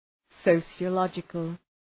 Προφορά
{,səʋsıə’lɒdʒıkəl}